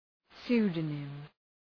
Προφορά
{‘su:dənım}